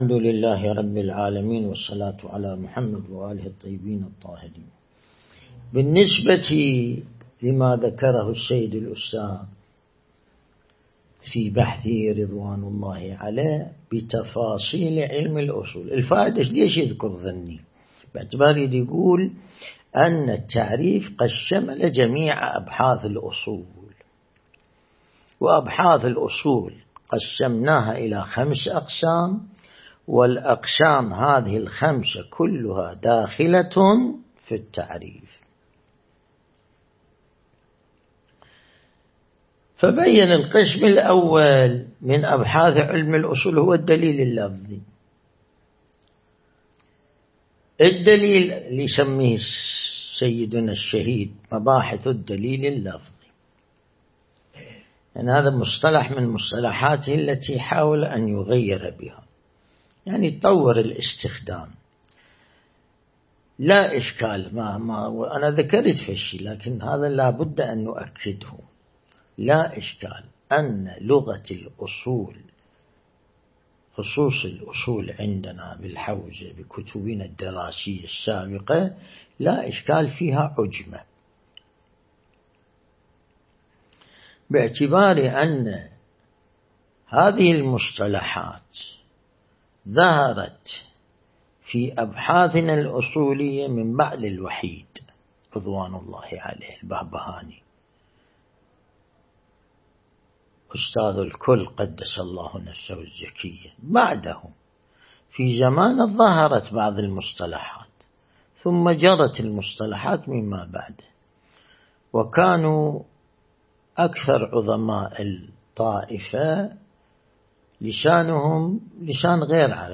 درس البحث الخارج الأصول (24)
النجف الأشرف